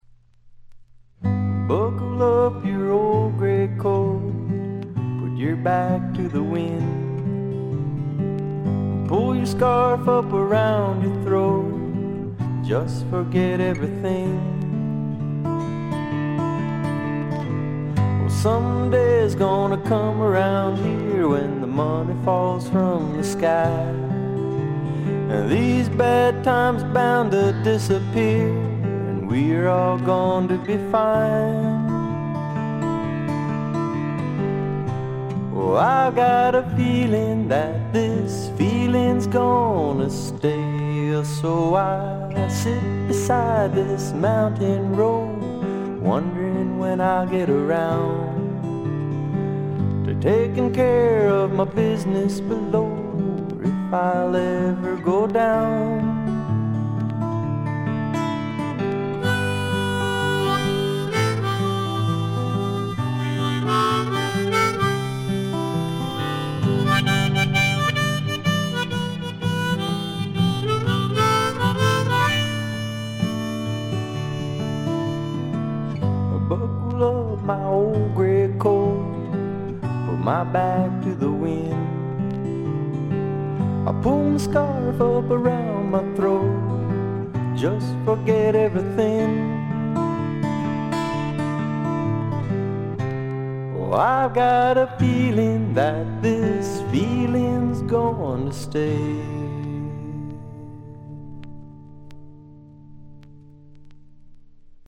軽微なバックグラウンドノイズ、チリプチ少し。
試聴曲は現品からの取り込み音源です。